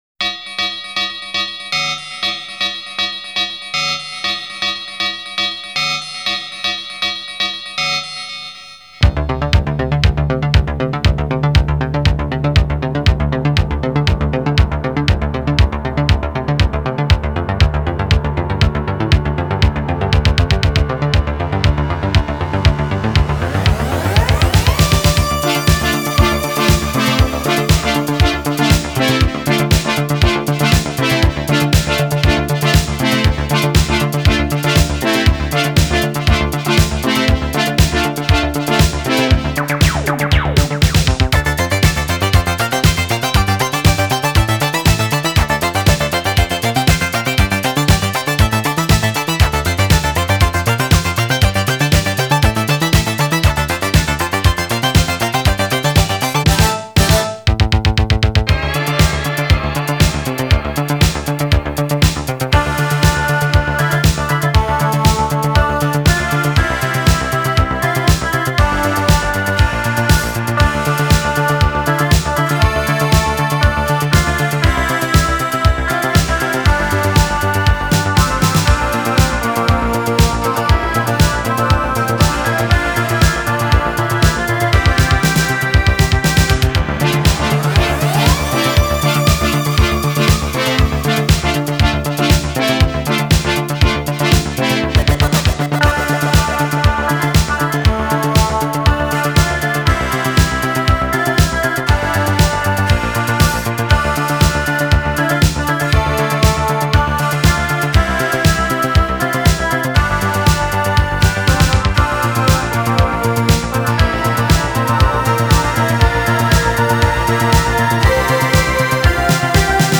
Genre: Spacesynth.